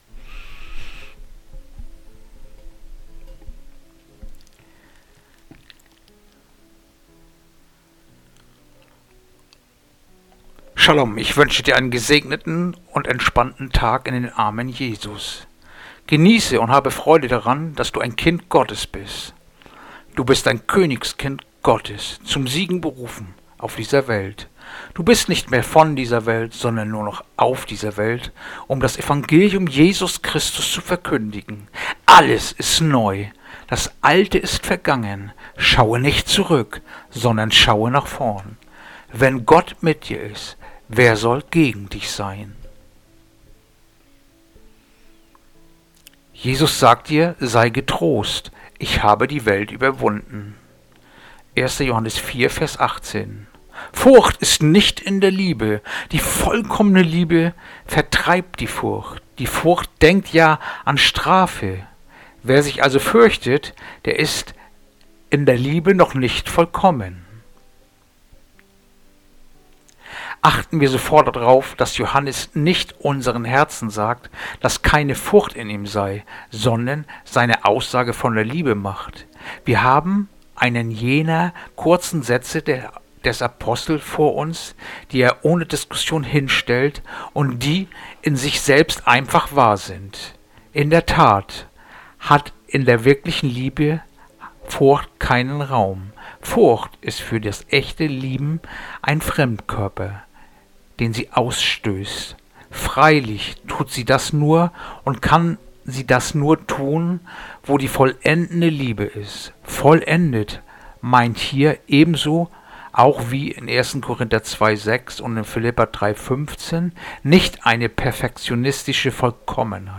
Andacht-vom-11-Mail-1.-Johannes-4-18